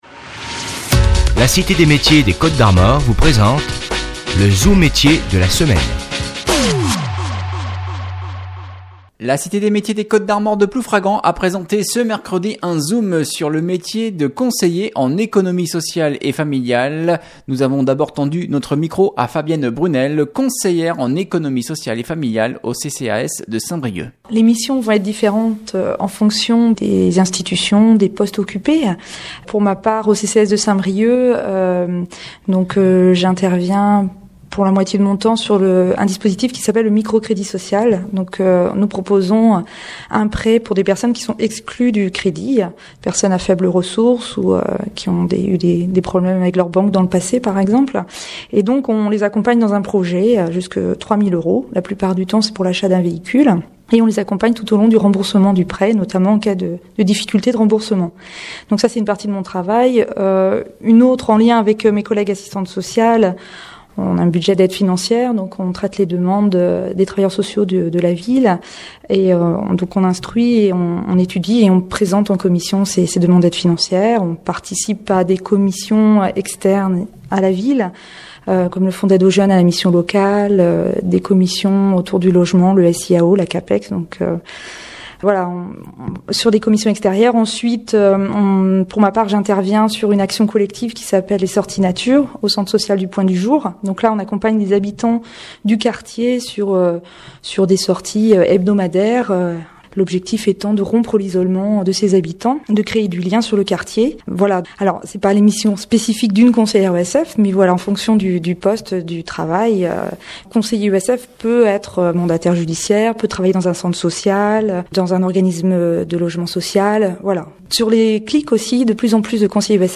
Présentation